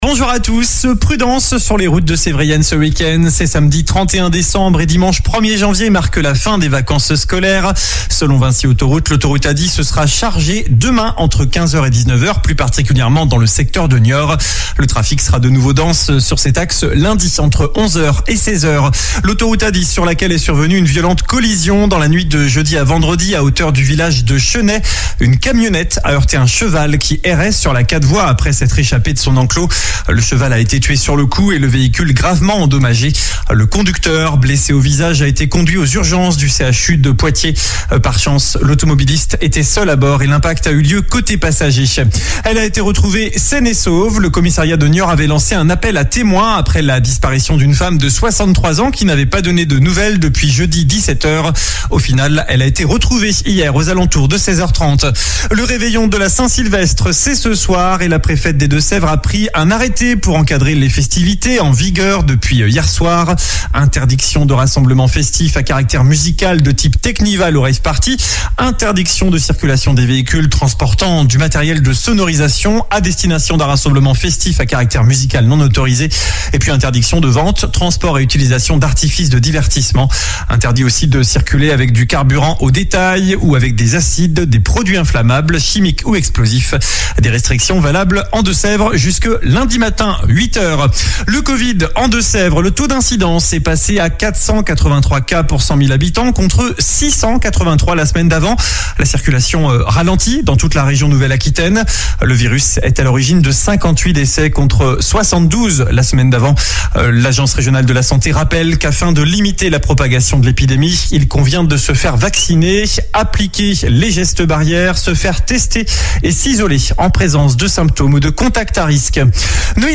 Journal du samedi 31 décembre